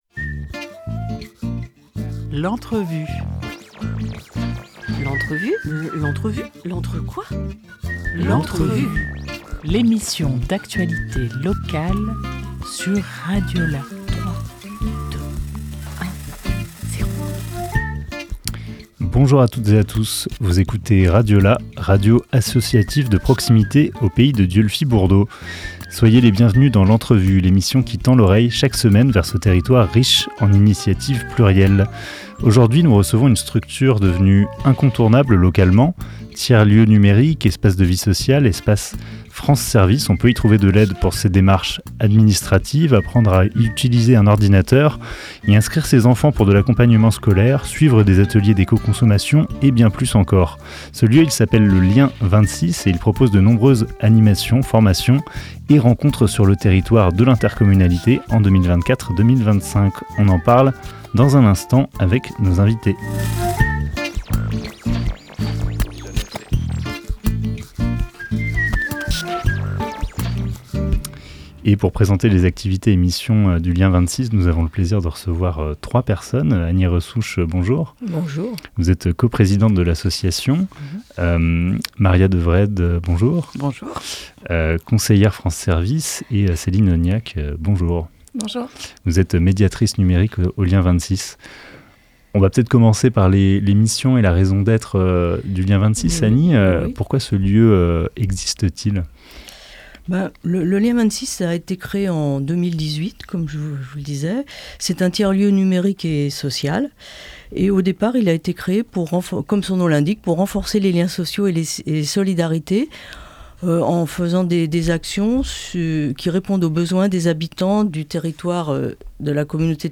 22 octobre 2024 11:37 | Interview
Rencontre radiophonique avec le Lien 26, tiers-lieu social et numérique labellisé France Services à Dieulefit.